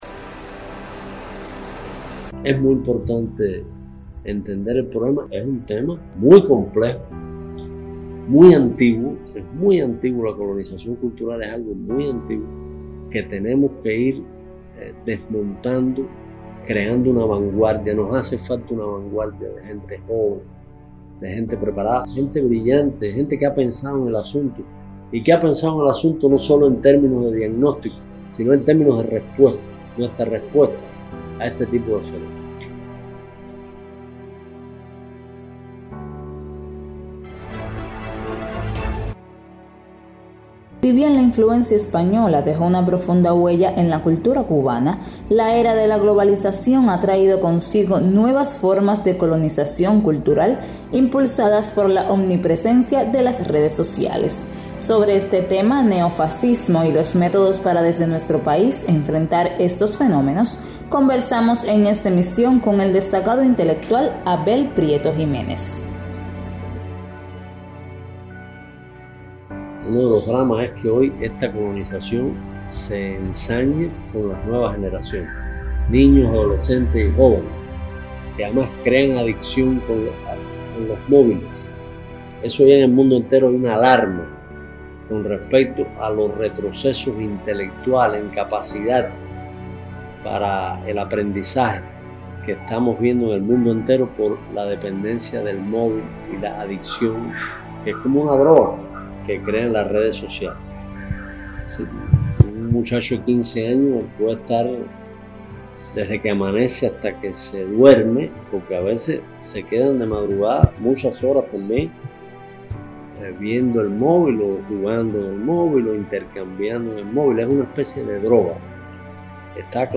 Si bien la influencia española dejó una profunda huella en la cultura cubana, la era de la globalización ha traído consigo nuevas formas de colonización cultural, impulsadas por la omnipresencia de las redes sociales. Sobre este tema, neofascismo y los métodos para desde nuestro país enfrentar estos fenómenos conversamos en esta emisión con el destacado intelectual Abel Prieto Jiménez.